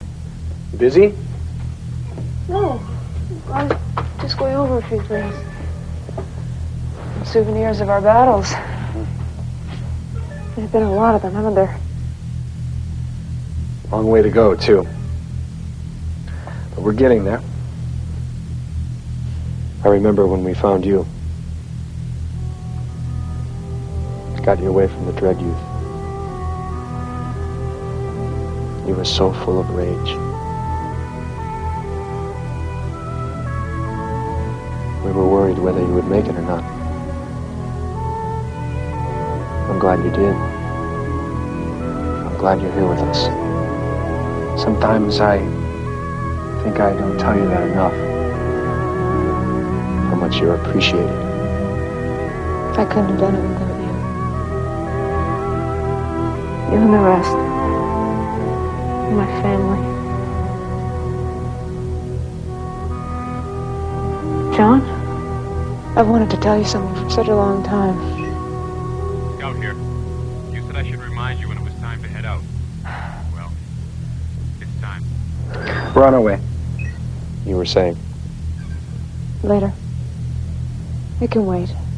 "Retribution,Part II" - Pilot and Power's 'It Can Wait' Conversation